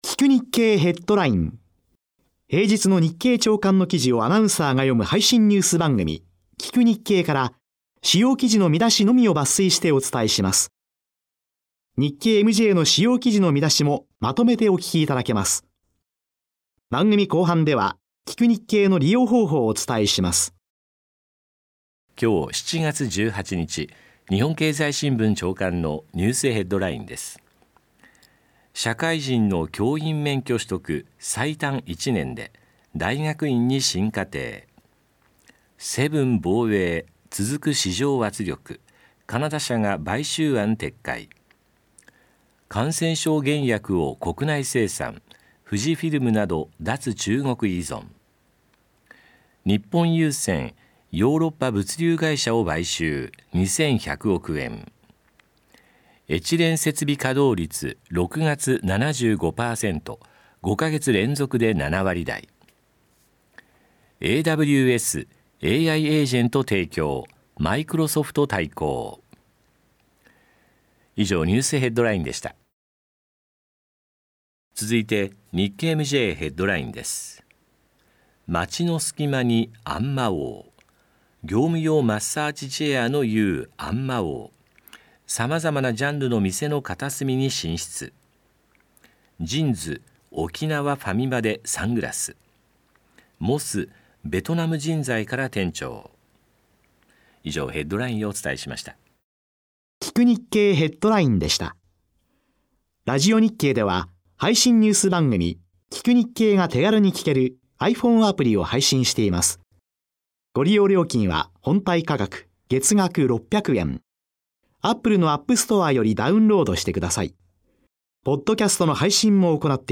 … continue reading 3216 एपिसोडस # ビジネスニュース # 日本 経済学 # NIKKEI RADIO BROADCASTING CORPORATION # ビジネス # ニュース